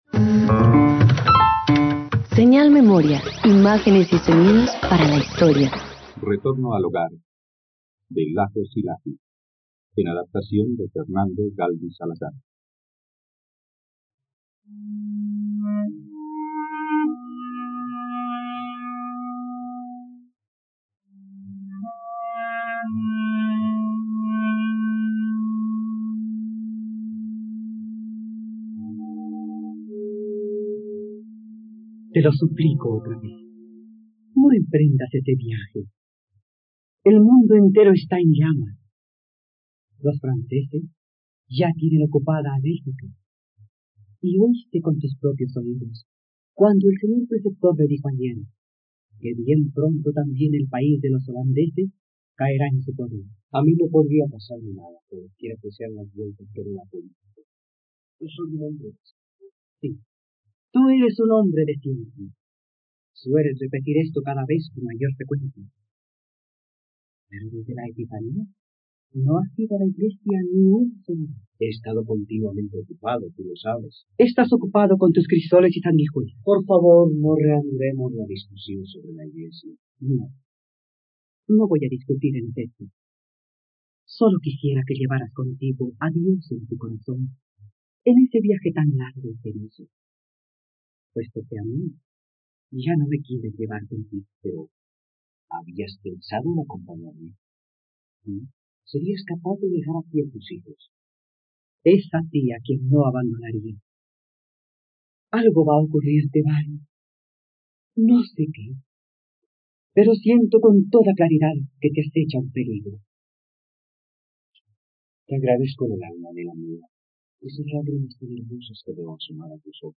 Retorno al hogar - Radioteatro dominical | RTVCPlay
..Radioteatro. Escucha ‘Retorno al hogar’, una adaptación radiofónica basada en la obra de Lajos Zilahy.